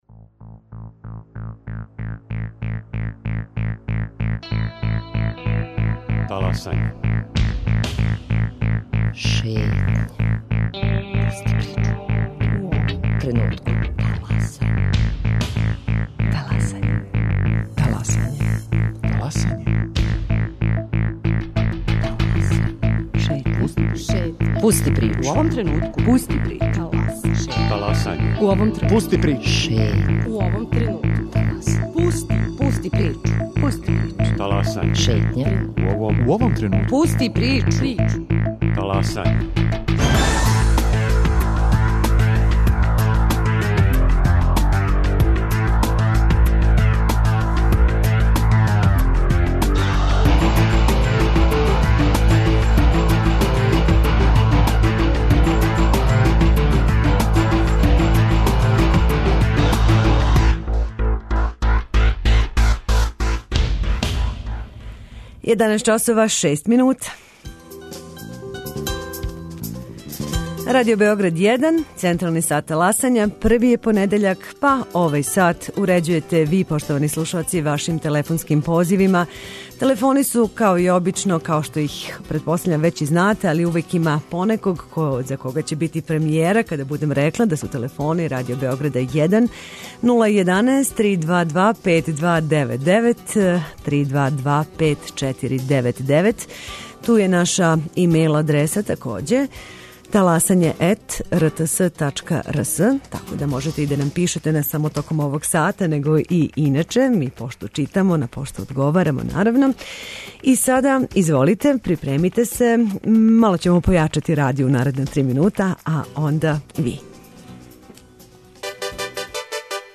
Контакт програм Радио Београда 1! Ваше предлоге, примедбе и похвале реците јавно, а ми ћемо слушати пажљиво!